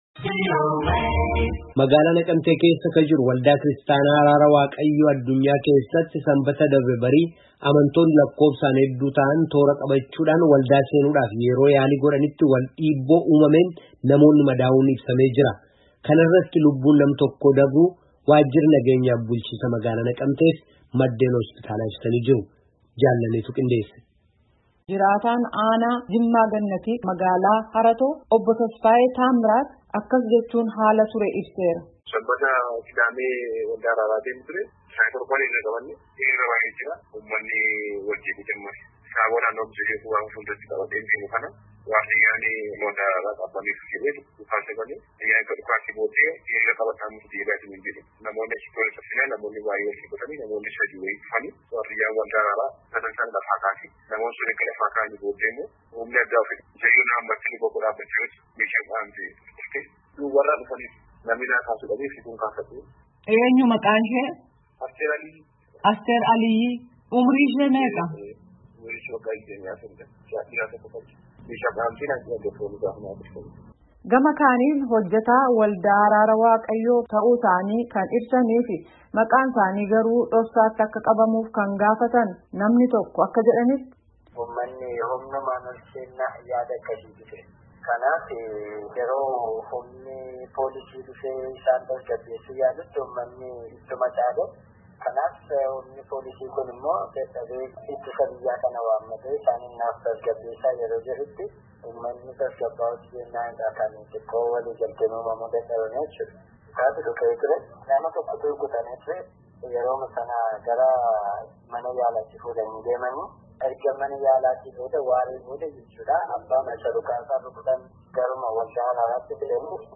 Gabaasa Guutuu Caqasaa